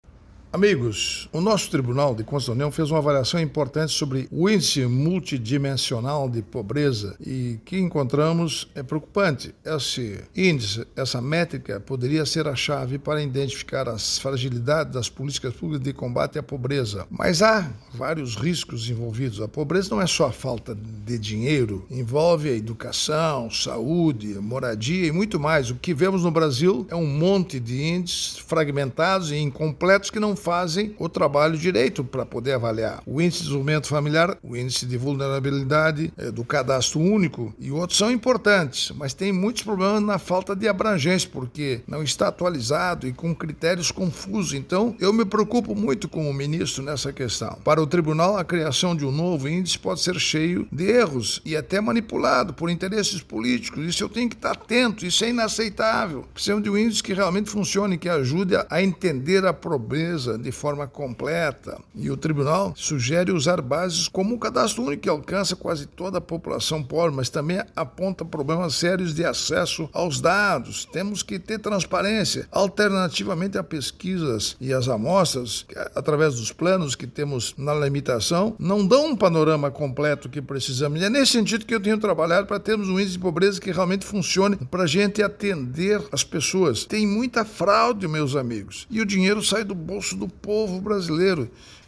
Comentário do ministro Augusto Nardes.